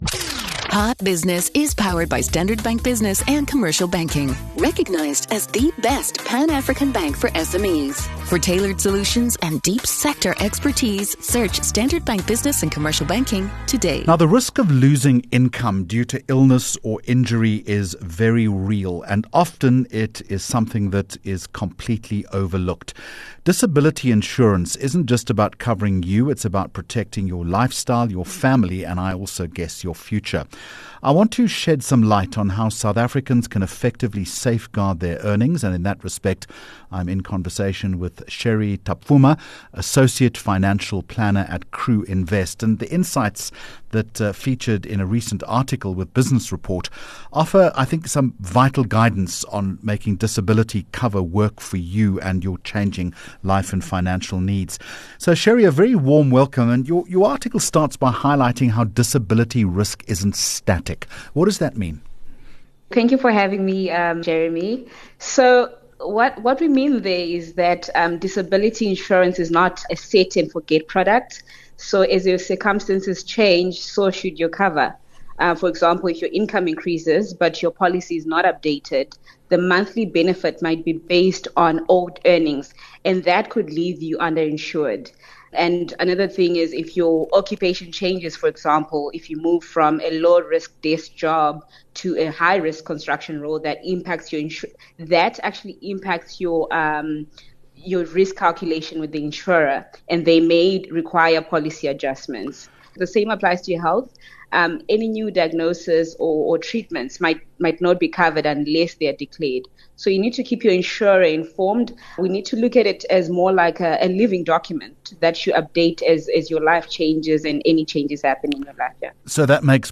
17 Jul Hot Business Interview